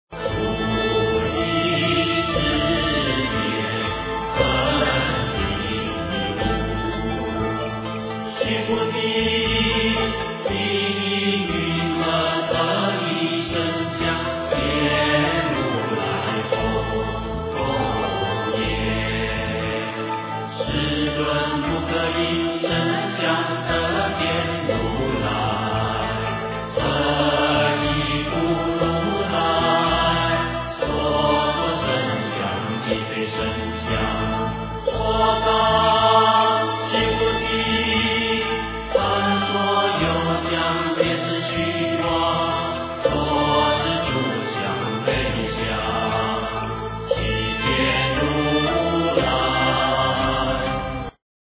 金刚经-如理实见分第五 诵经 金刚经-如理实见分第五--未知 点我： 标签: 佛音 诵经 佛教音乐 返回列表 上一篇： 金刚经-大乘正宗分第三 下一篇： 圆觉经 相关文章 老实念佛人--蔡可荔 老实念佛人--蔡可荔...